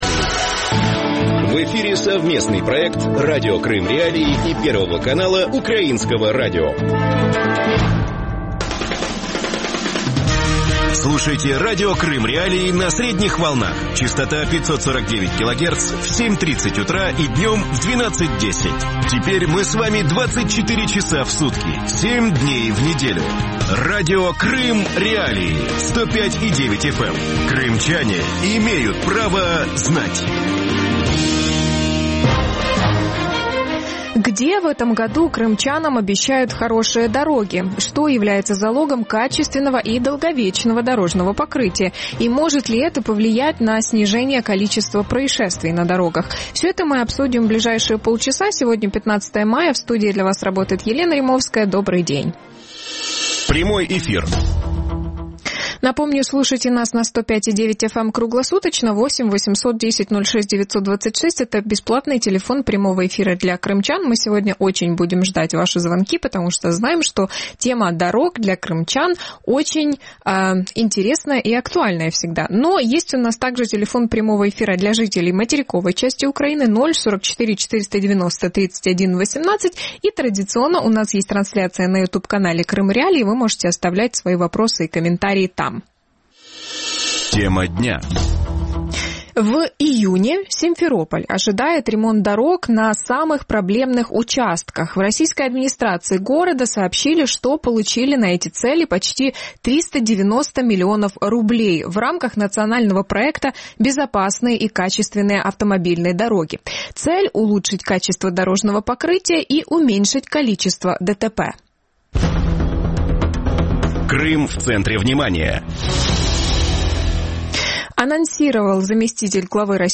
И может ли улучшение качества асфальта повлиять на снижение количества происшествий на дорогах? Гости эфира